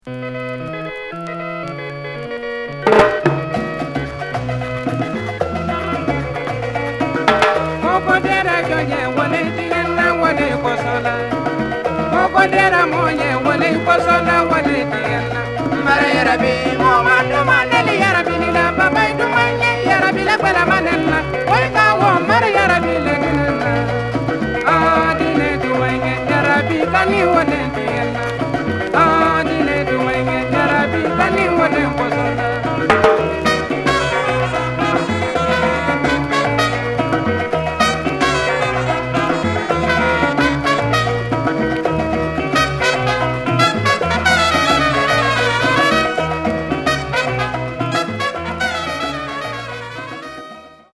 キーワード：アフリカ　サイケ 　ファンク